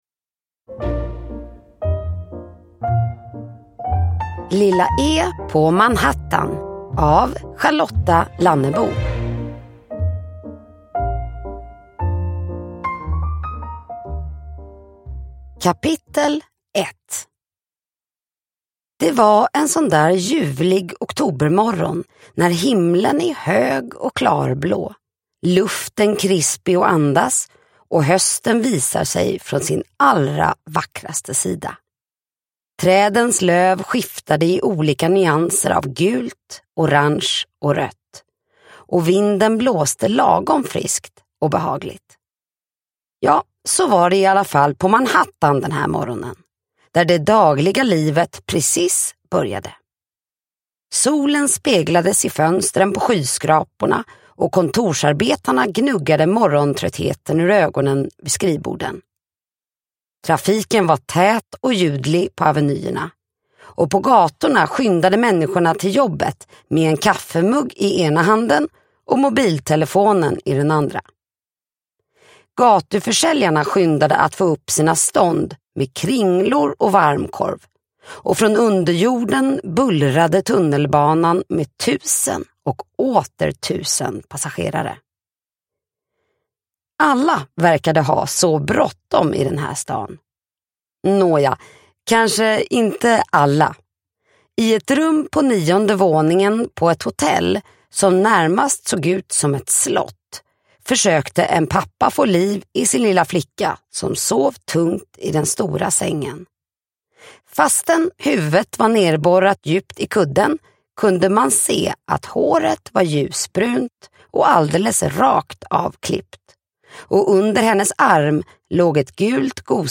Lilla E på Manhattan – Ljudbok – Laddas ner